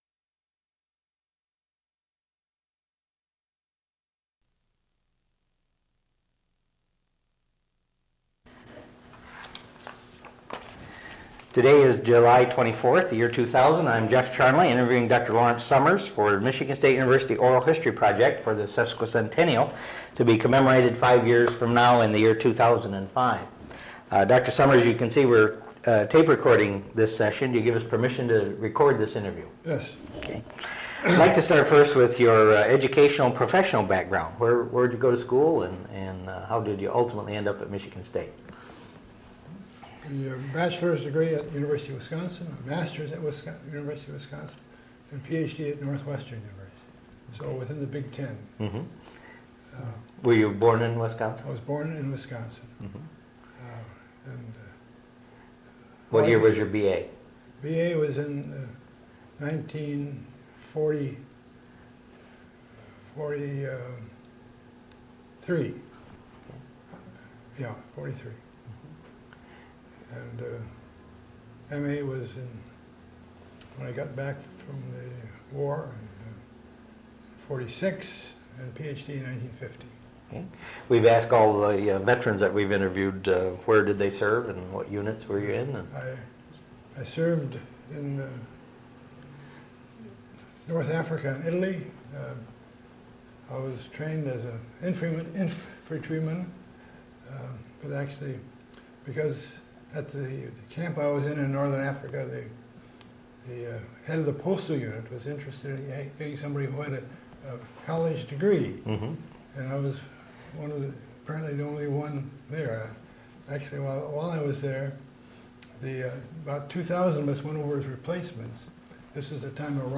Interview
Original Format: Audiocassettes